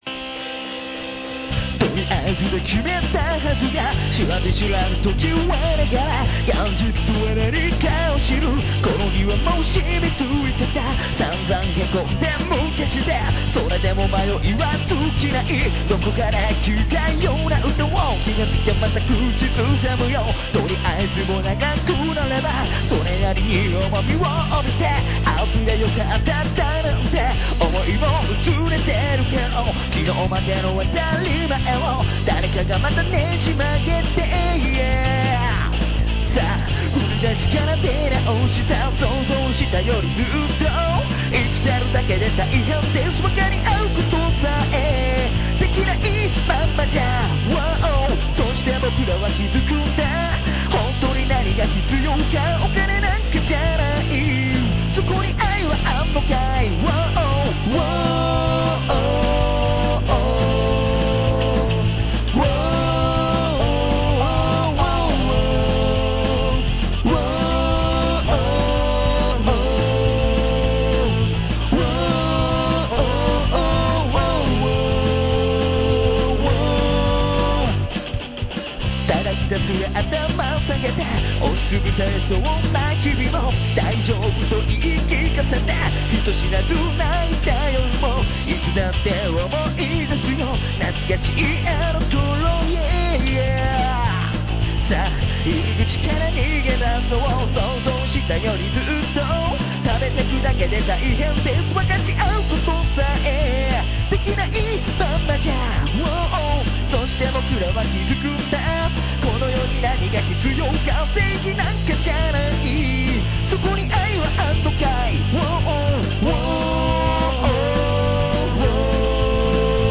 例によって部屋で毛布をかぶって録音しているので声にノビがありませんがライブではもっと盛り上がる感じに仕上がると思います。